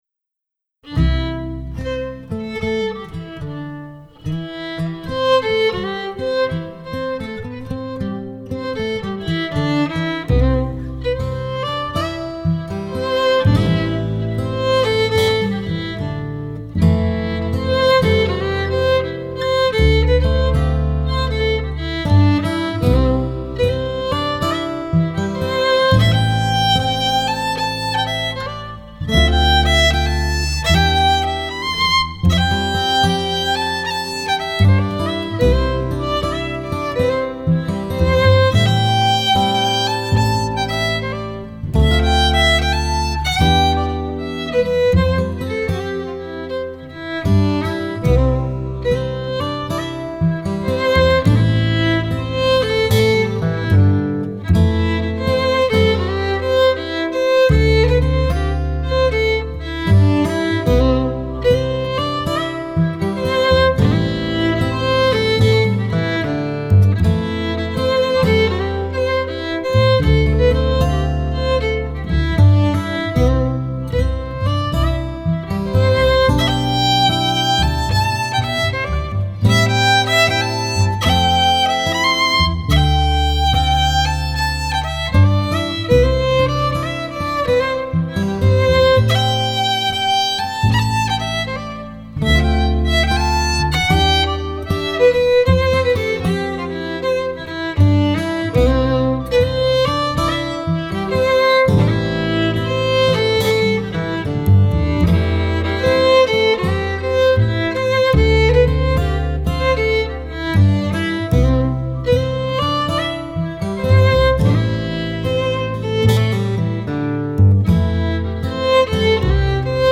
Scottish and Québécois fiddling.
à la guitare
au piano
aux percussions